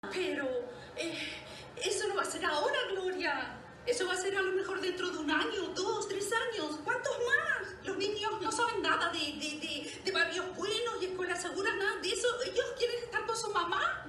TV Rolle - Orange Is The New Black - Mexikanisch